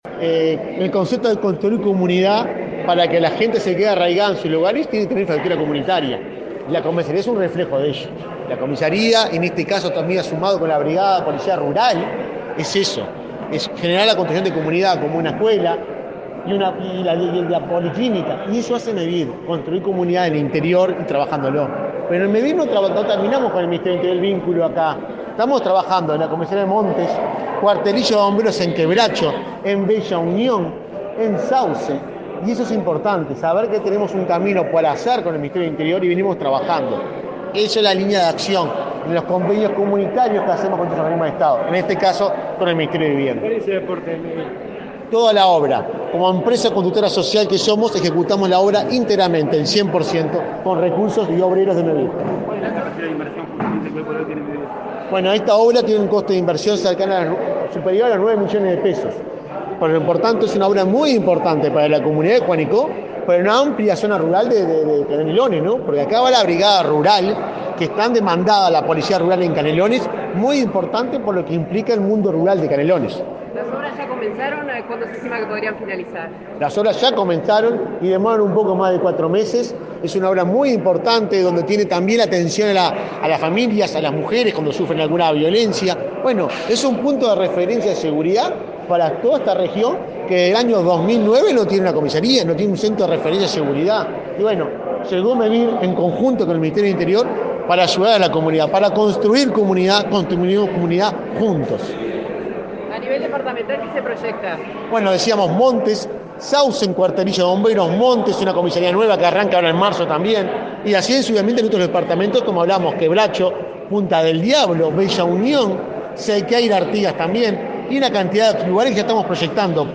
Declaraciones a la prensa del presidente de Mevir
Luego, Delgado dialogó con la prensa.